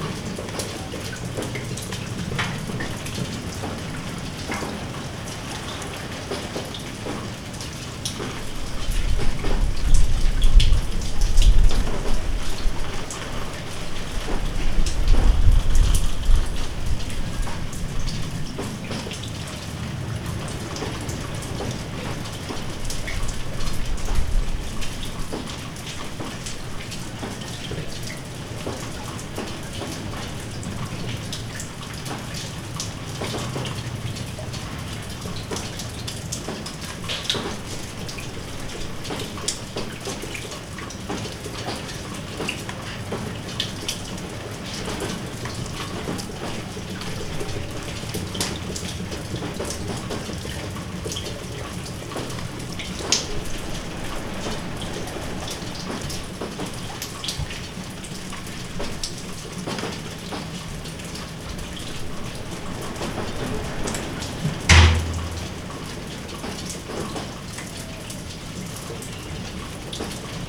3d_audio_examples